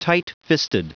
Prononciation du mot tightfisted en anglais (fichier audio)
Prononciation du mot : tightfisted